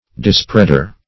Search Result for " dispreader" : The Collaborative International Dictionary of English v.0.48: Dispreader \Dis*pread"er\, n. One who spreads abroad.